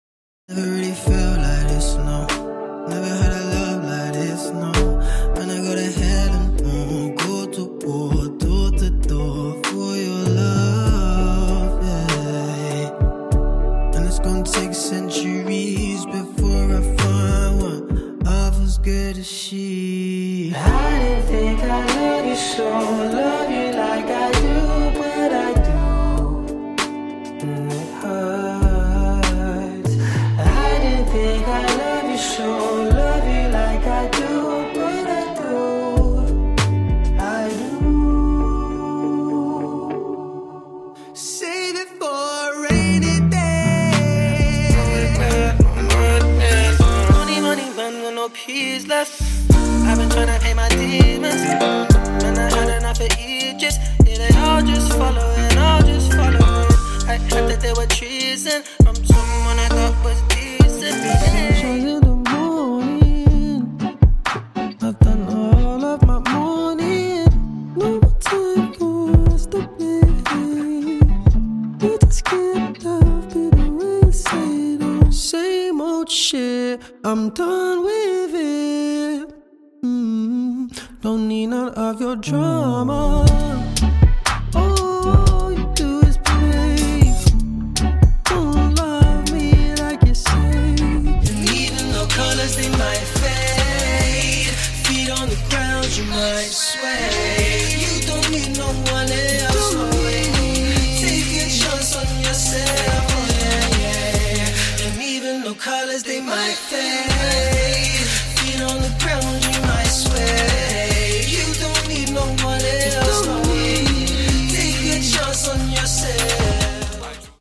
Commercial Showreel Squarespace Sky Sports Golf Spotify Adidas Dominos Virgin Mobile Inside The Force The Timekeepers – First Flight The Timekeepers – Ancient Olympics The Alchemist by Paulo Coelho Singing Voicereel